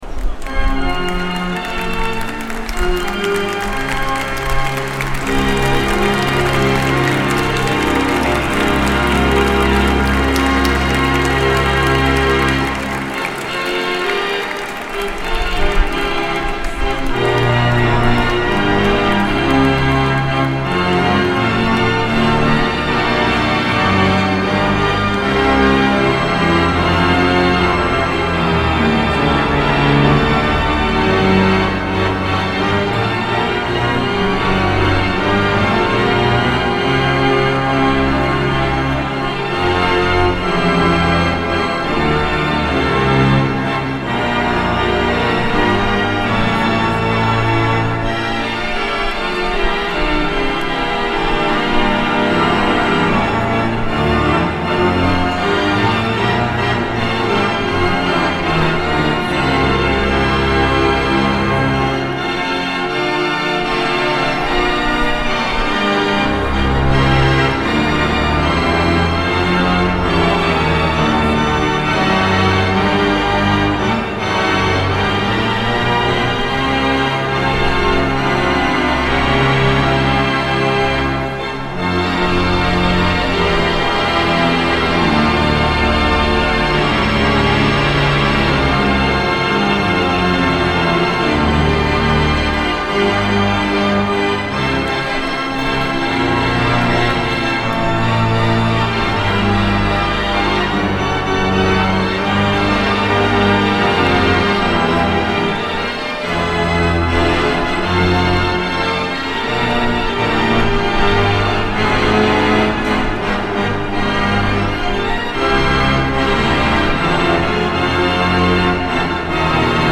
26-FinaleOrgano OrdinazArcivescovo 31Lug22